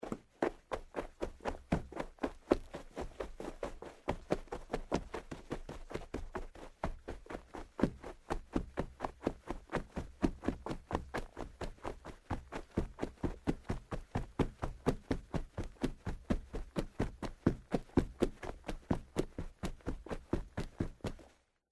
在薄薄的雪地上奔跑－YS070525.mp3
通用动作/01人物/01移动状态/02雪地/在薄薄的雪地上奔跑－YS070525.mp3
• 声道 立體聲 (2ch)